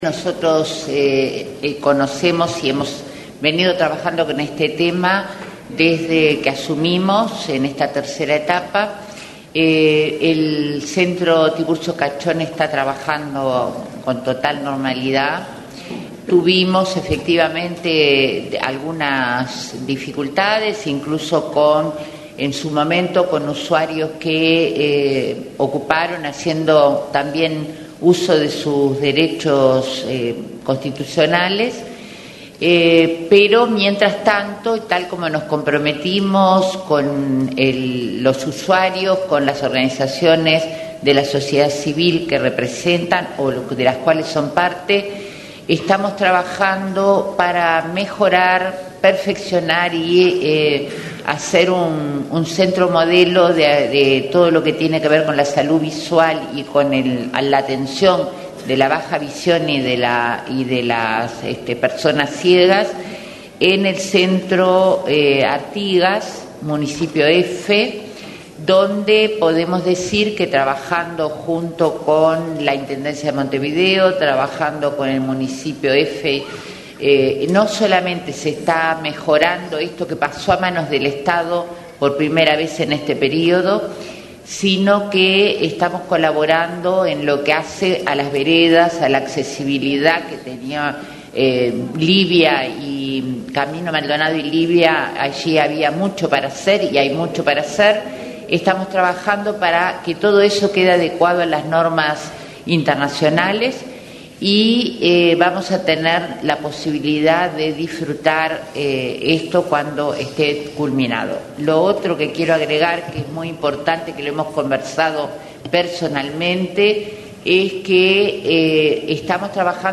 “El Centro Tiburcio Cachón está trabajando con total normalidad”, afirmó la ministra de Desarrollo Social, Marina Arismendi, en el Consejo de Ministros abierto en La Teja, al tiempo que destacó que se trabaja para hacer del Instituto de Ciegos General Artigas un centro modelo de atención a la salud visual en todo el país. Explicó que junto con la Intendencia de Montevideo se trabaja en mejorar la accesibilidad al centro.